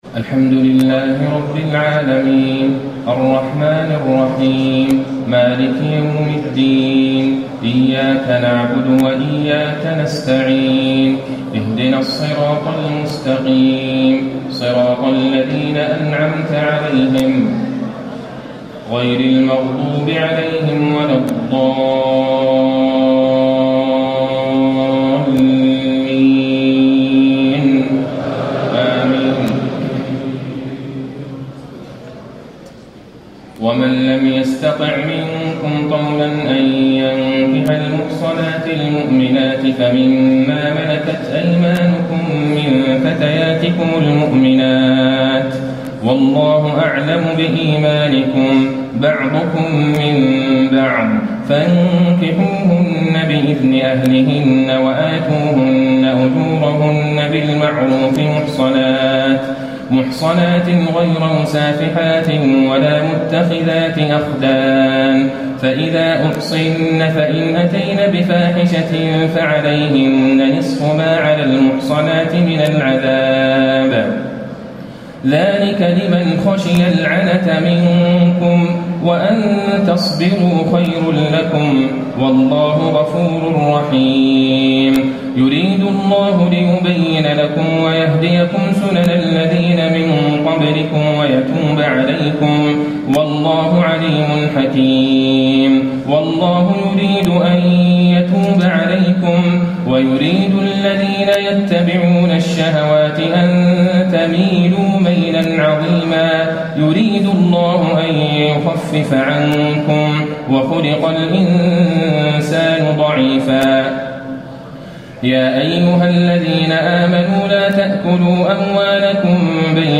تهجد ليلة 25 رمضان 1435هـ من سورة النساء (25-100) Tahajjud 25 st night Ramadan 1435H from Surah An-Nisaa > تراويح الحرم النبوي عام 1435 🕌 > التراويح - تلاوات الحرمين